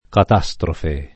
catastrofe [ kat #S trofe ] s. f.